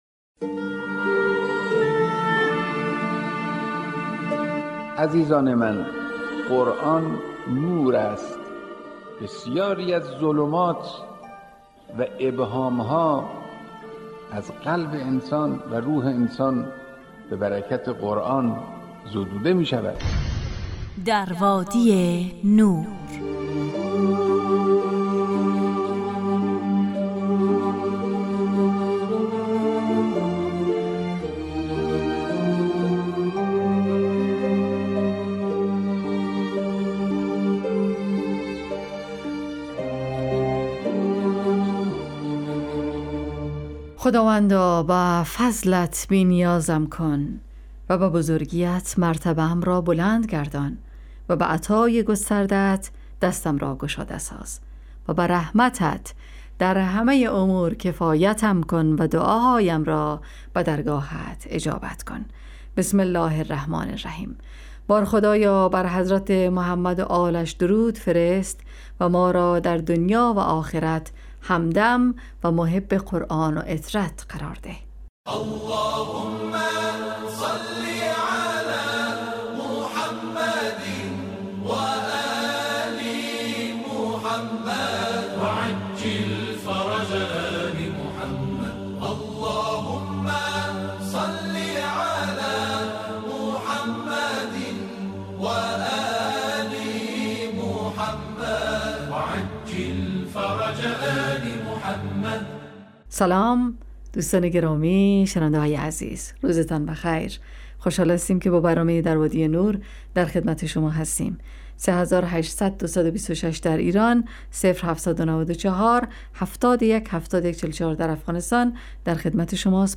ایستگاه تلاوت